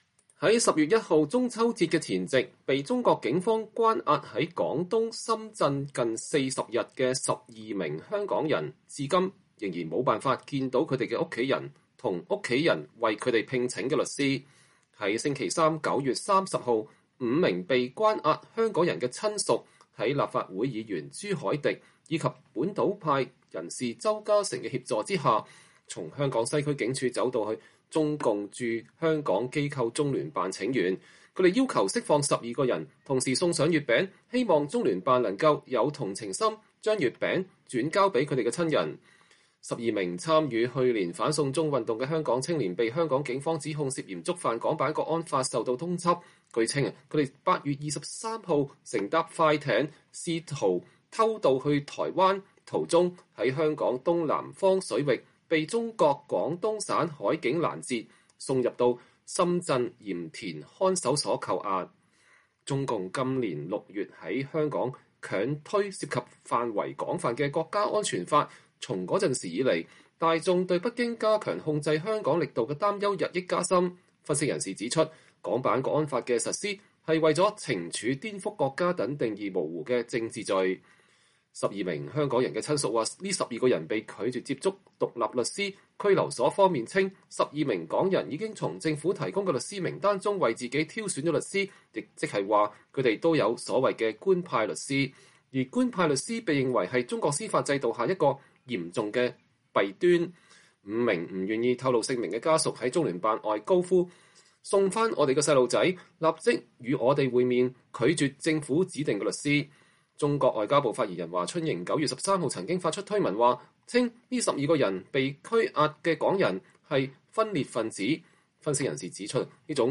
12港人的親屬在中聯辦前要求北京政府釋放她們被拘押在大陸的親人。（2020年9月30日）
5名不願意透露姓名的家屬在中聯辦外高呼“送回我們的孩子”、“立即與我們會面”、“拒絕政府指定的律師”。